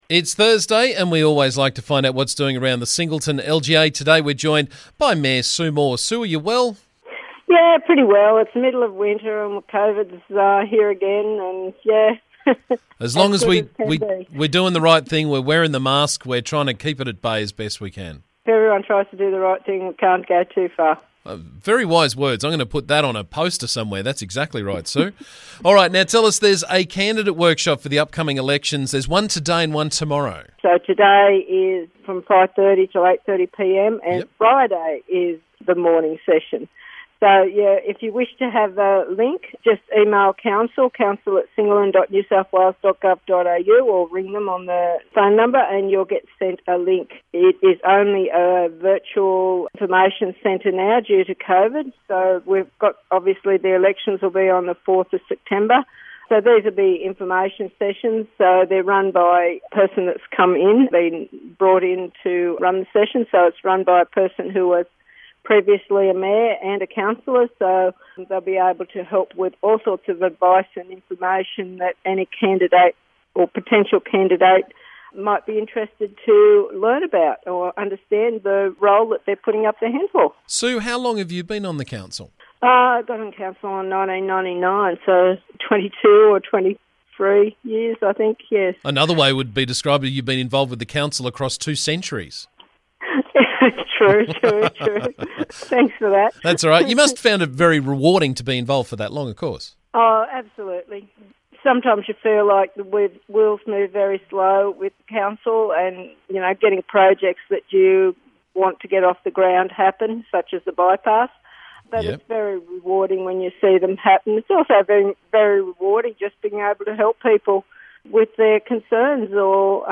Every couple of weeks we catch up with Singleton Council Mayor Sue Moore to find out what's happening around the district.